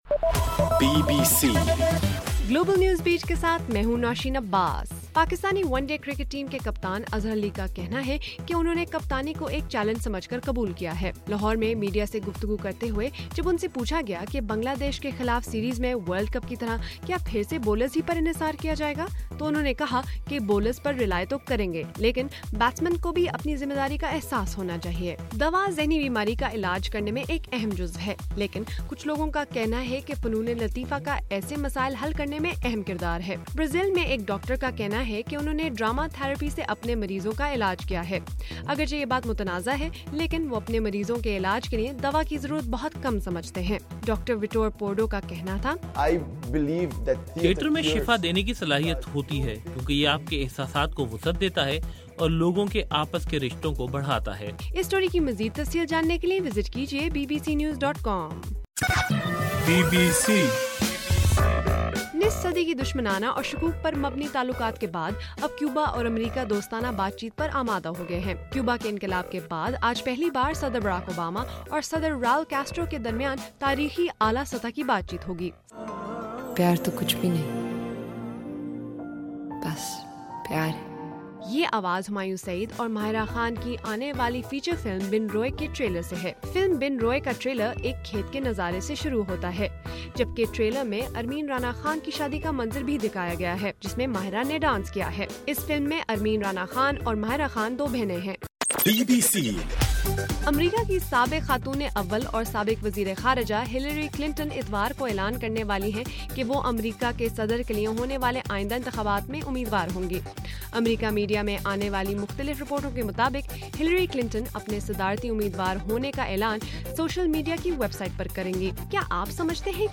اپریل 12: صبح 1 بجے کا گلوبل نیوز بیٹ بُلیٹن